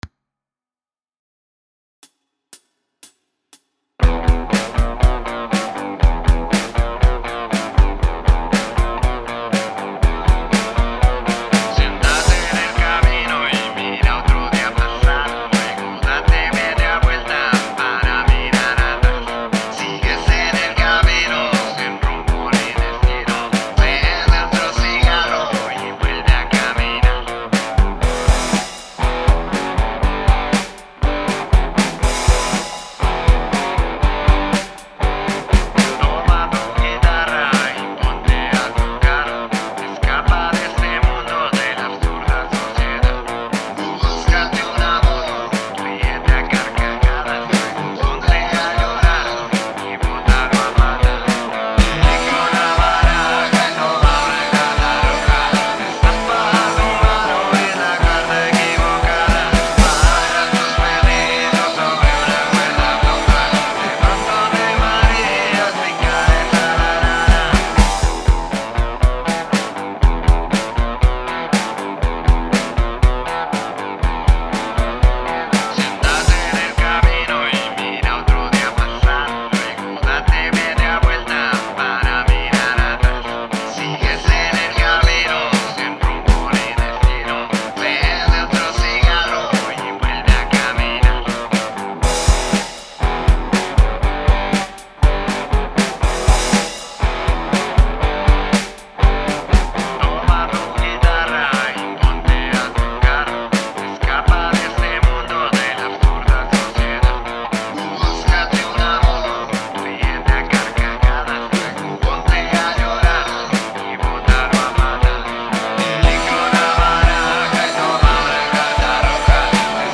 Compongo, canto y toco la guitarra.
Rock Alternativo